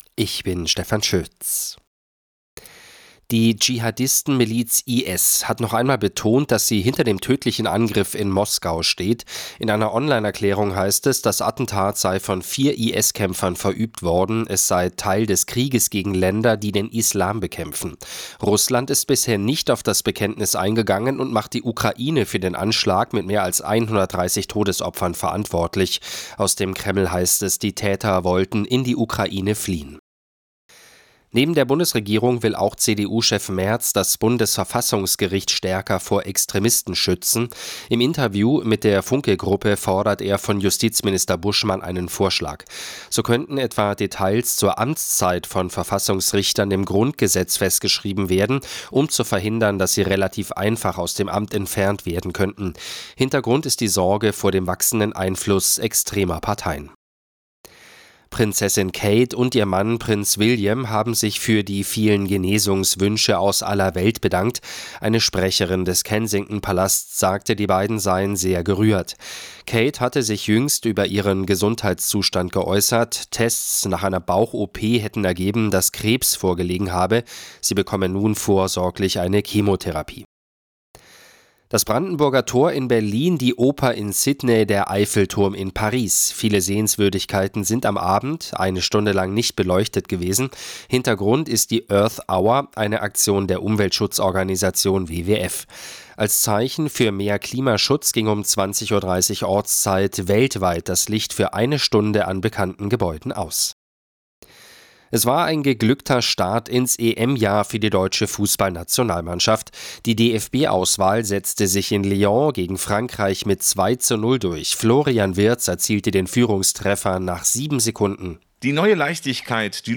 Die aktuellen Nachrichten von Radio Arabella - 24.03.2024